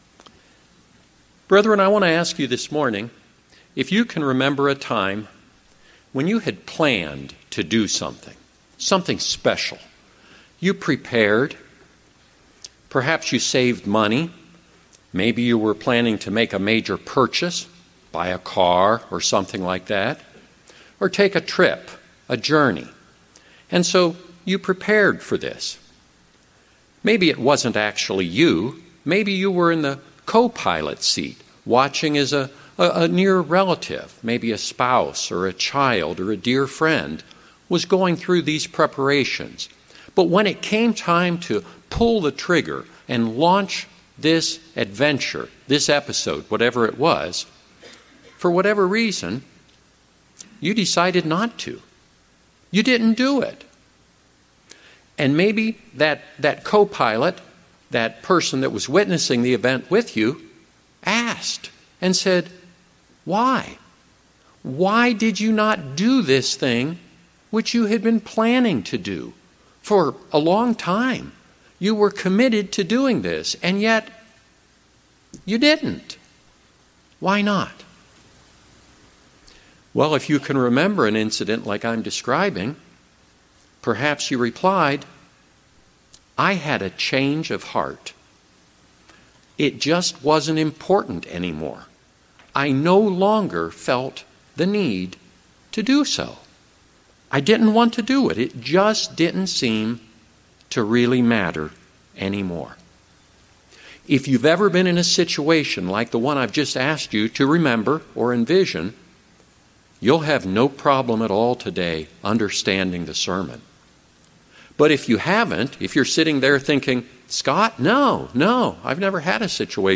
Sermons
Given in Rapid City, South Dakota